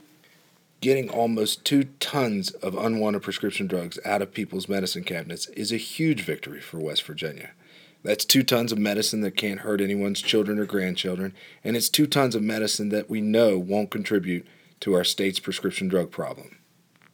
Click here listen to an audio clip from U.S. Attorney Goodwin regarding Saturday’s Take-Back results